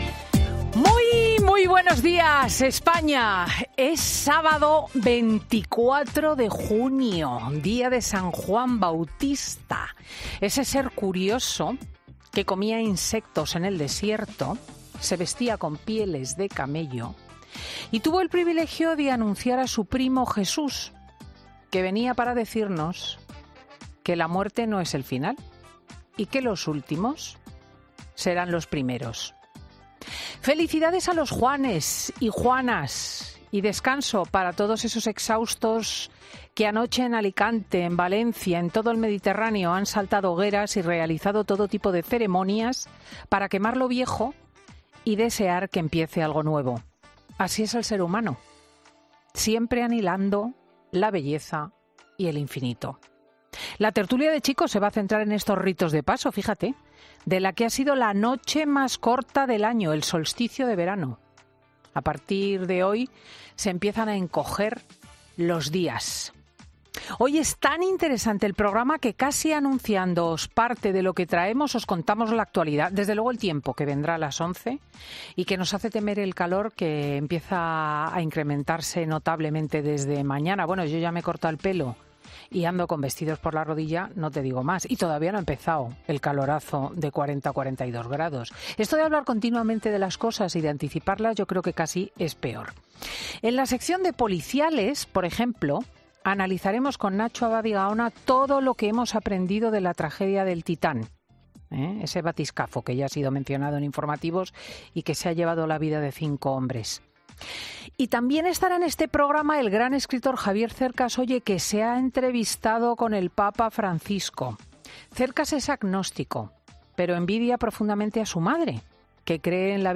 Escucha el monólogo de Cristina L. Schlichting con el análisis político de cara a las elecciones del 23J